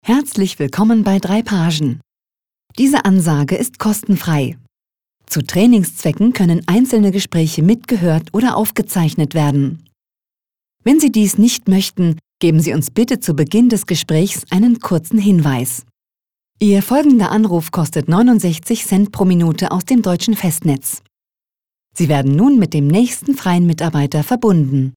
Deutsche Sprecherin für Funkspots, TV-Spots, Industriefilm, Warteschleife, Anrufbeantworter. Stimmlage: mittel, weich Stimmalter: 25-40
Sprechprobe: eLearning (Muttersprache):